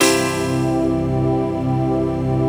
DM PAD5-1.wav